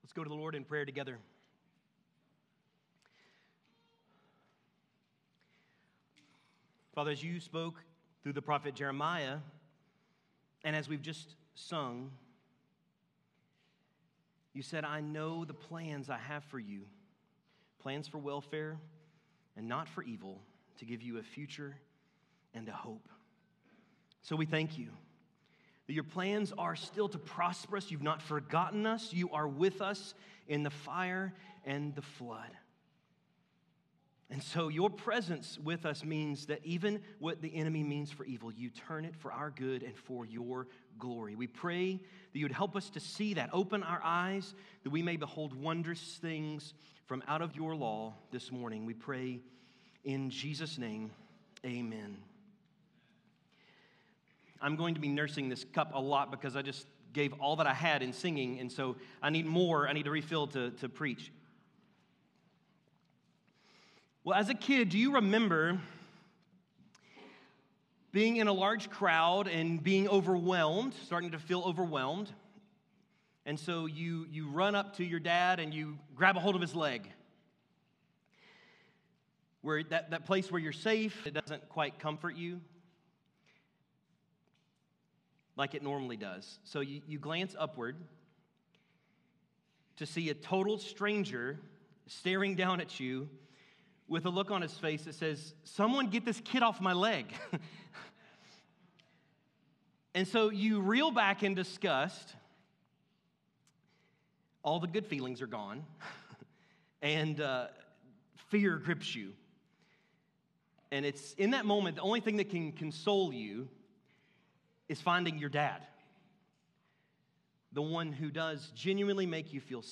A sermon from the Book of Isaiah at Oak Park Baptist Church in Jeffersonville, Indiana